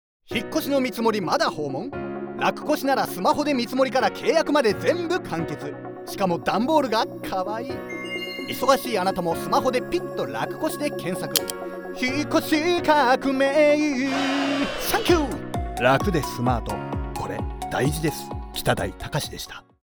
FM局でCM放送中！